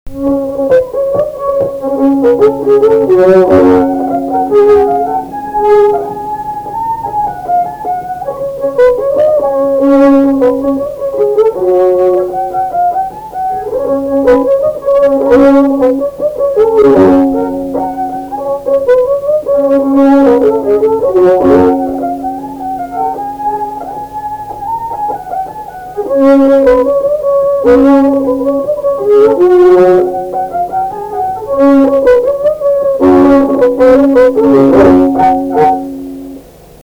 Valsas · LMTA Muzikinio folkloro archyvas · omeka
Kareiviškės
instrumentinis
smuikas